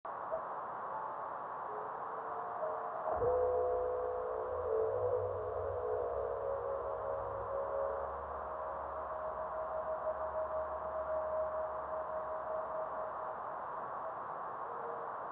video and stereo sound:
Good sonic specimen.
Radio spectrogram of the time of the above meteor.  61.250 MHz reception above white line, 83.250 MHz below white line.